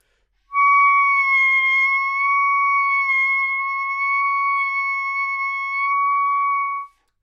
单簧管单音（吹得不好） " 单簧管 C6 音高不稳错误
描述：在巴塞罗那Universitat Pompeu Fabra音乐技术集团的goodsounds.org项目的背景下录制。单音乐器声音的Goodsound数据集。
标签： 纽曼-U87 单簧管 单注 多重采样 好声音 Csharp6
声道立体声